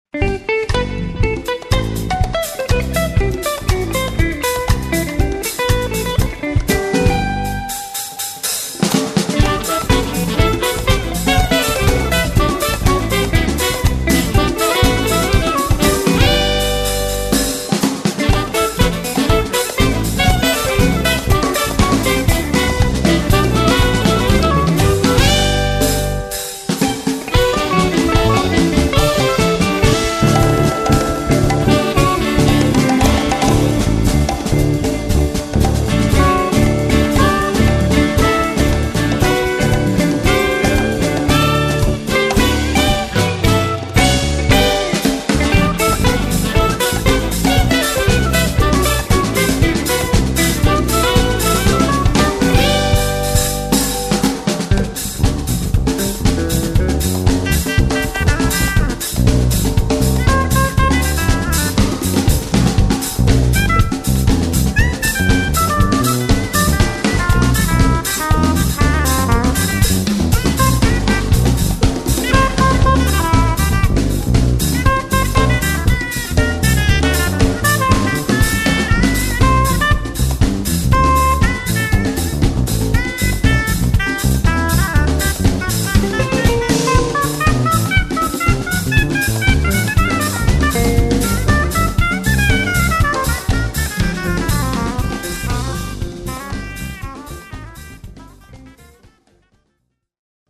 violina, vokal
saksofoni, flavta
kitara, vokal, douss’n gouni, banjo, balafon
bas kitara, kontrabas
bobni, tolkala
Posneto v živo Jazz Festival Cerkno, 26. maj 2001